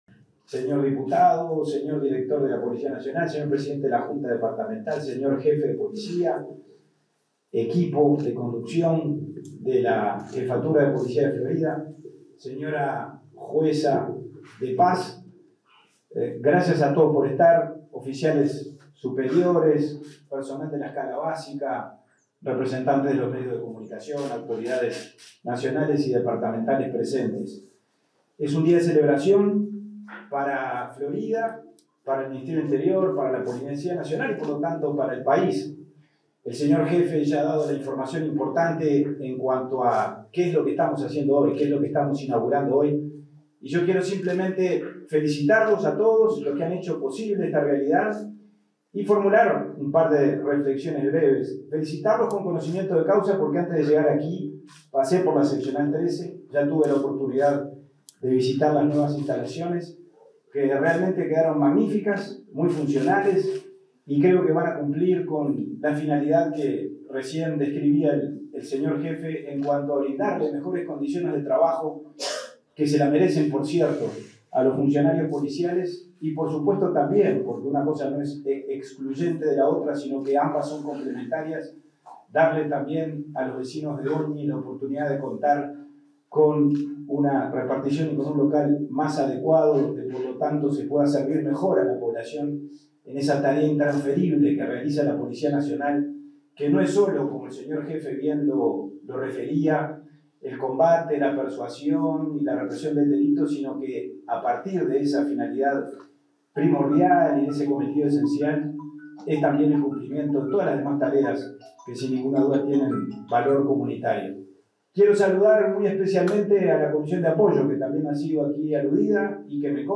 Palabras del subsecretario del Interior, Pablo Abdala
En el marco de la inauguración del local de la seccional 13.ª en Goñi, este 21 de agosto, se expresó el subsecretario del Interior, Pablo Abdala.